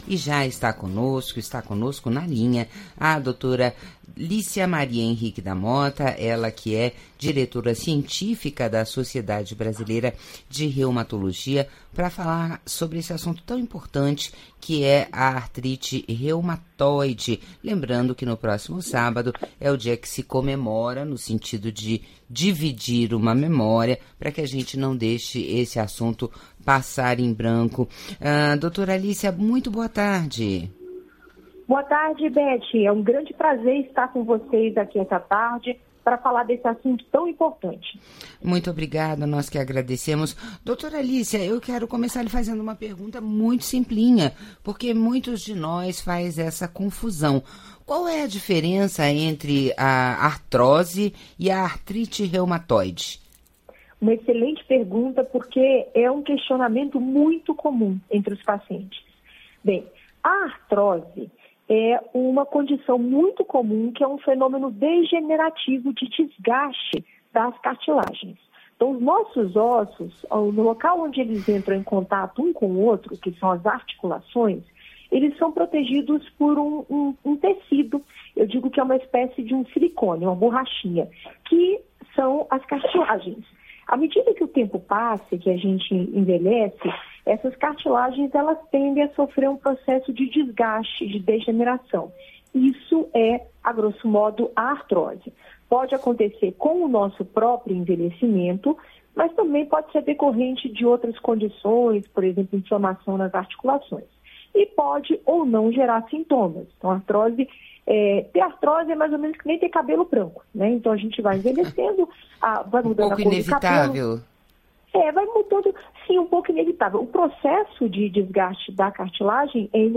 em entrevista à Rádio Nacional, da Agência EBC, esclareceu sobre sintomas, diagnóstico e tratamentos disponíveis para a Artrite Reumatoide. A especialista alertou a importância do tratamento precoce e chamou atenção da população para a disponibilidade de tratamentos pelo Sistema Único de Saúde (SUS).